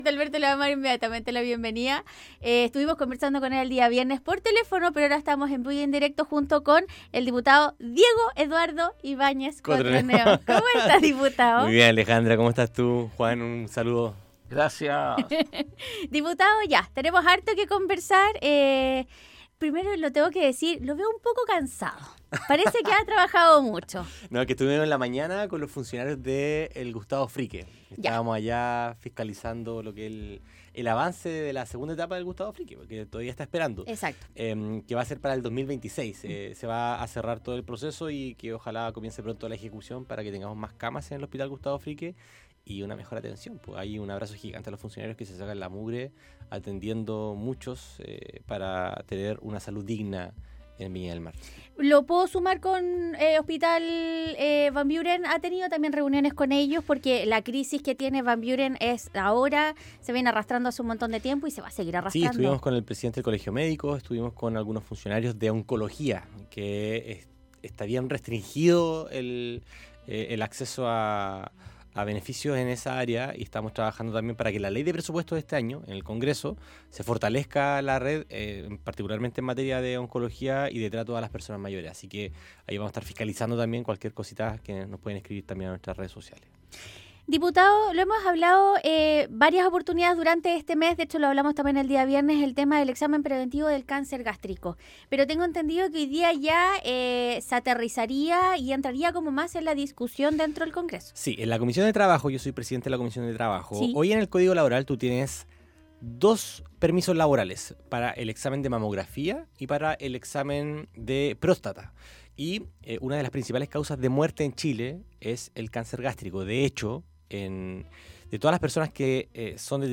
Esta mañana el Diputado del Distrito 6 habló del proyecto de ley sobre permiso laboral para examen preventivo de cáncer gástrico, proyecto de ley que obliga control biométrico en Uber y levantamiento de secreto bancario.